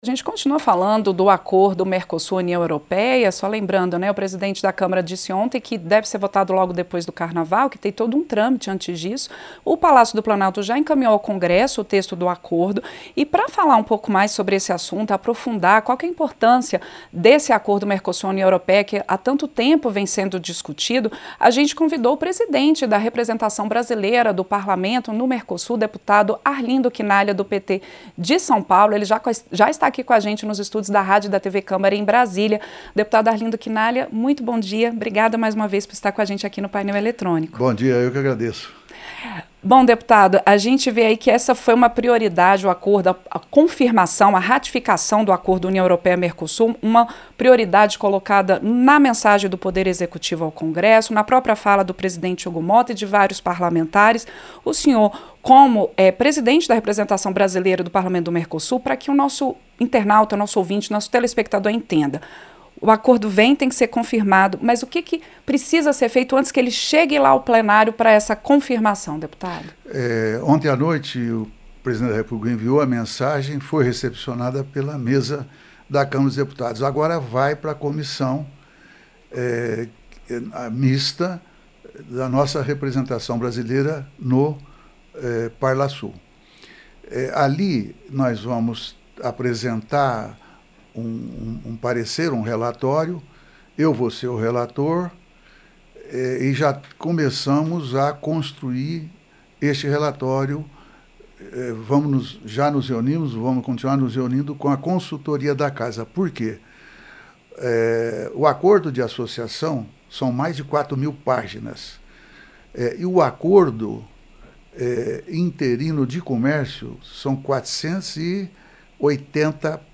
Entrevista - Dep. Arlindo Chinaglia (PT-SP)